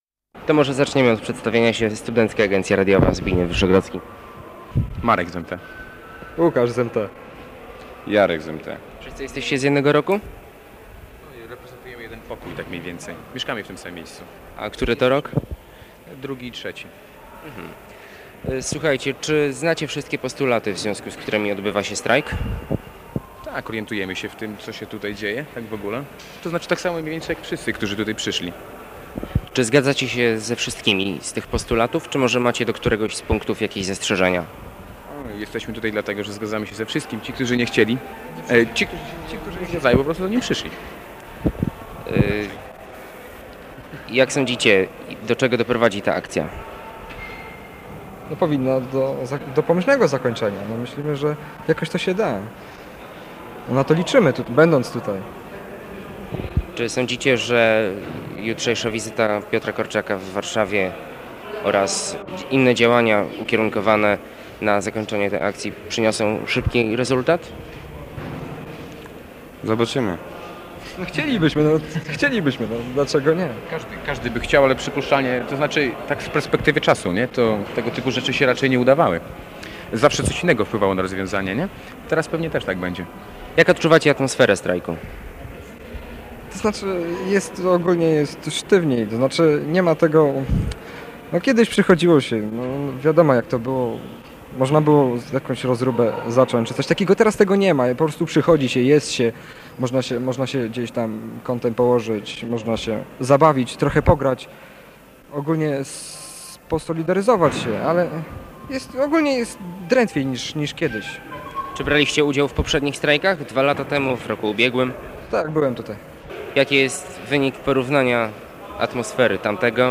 Rozmowa ze uczestnikami strajku - studentami Wydziału MT